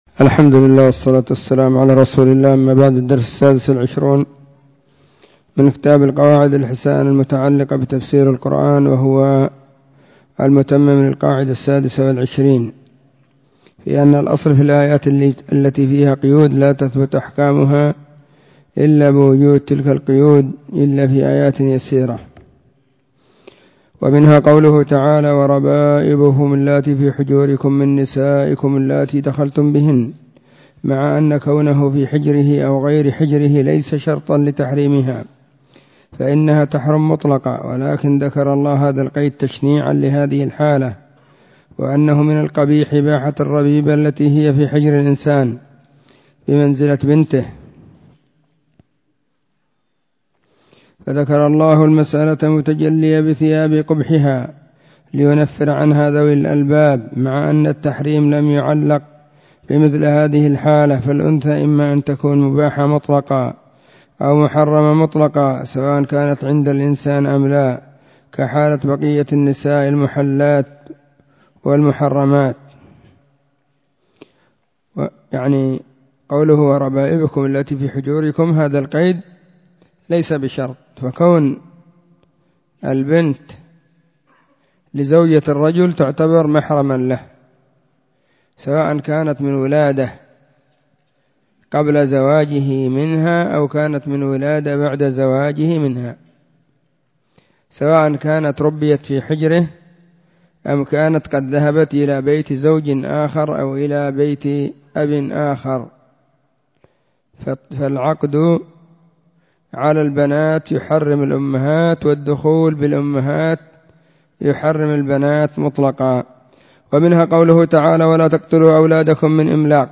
🕐 [بعد صلاة الظهر في كل يوم الخميس]